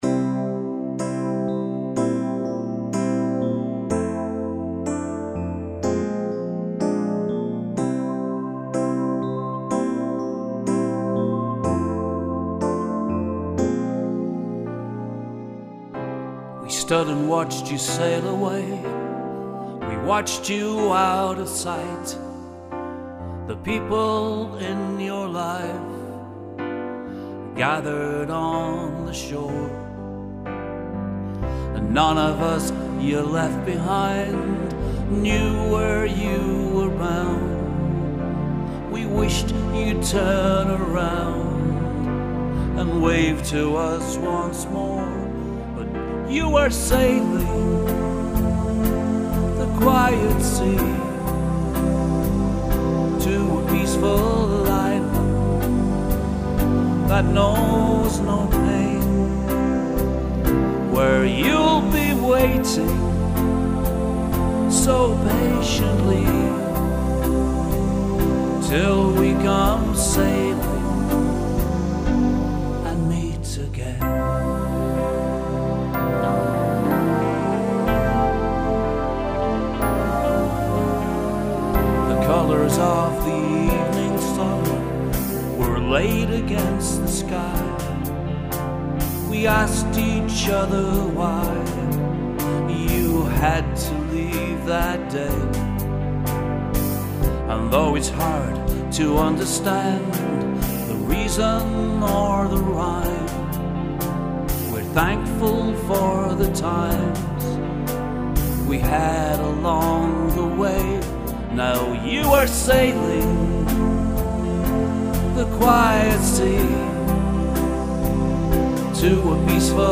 Song Type: Vocal
Recording Quality: Broadcast Master